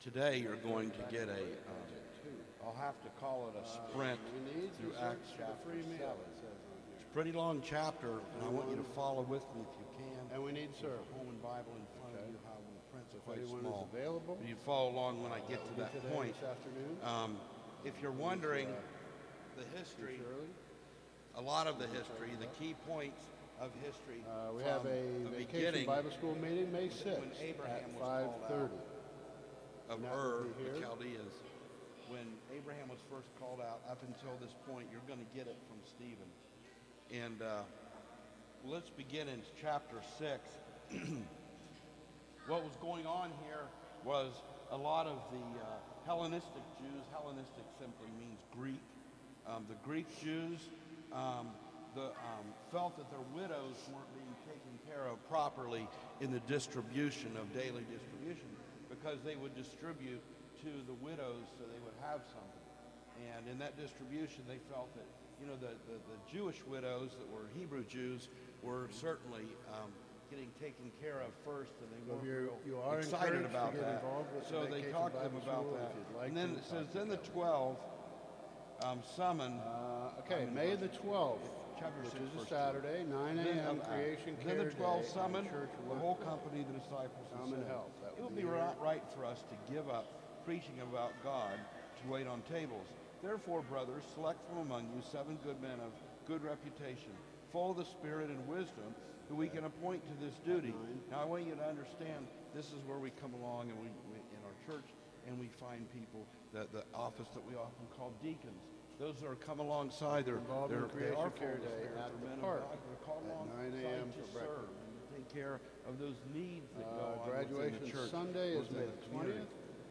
Sermons - Community Christian Fellowship